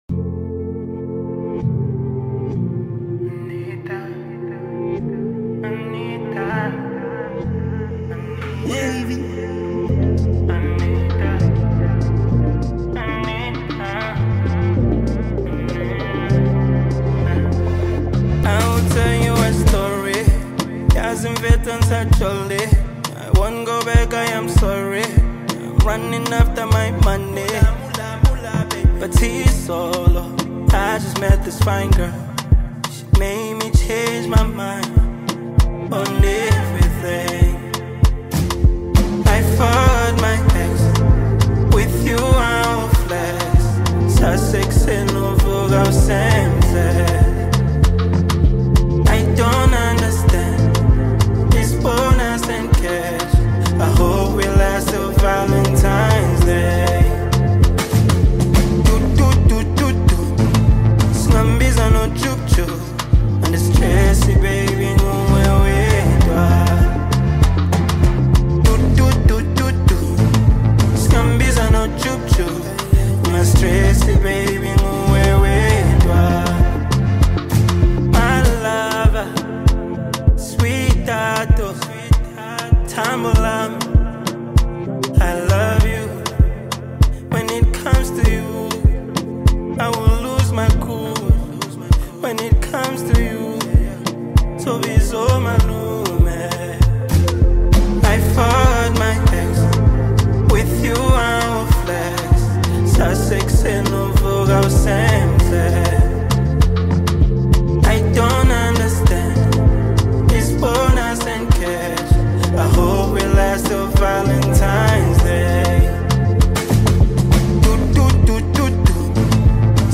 South African hip-hop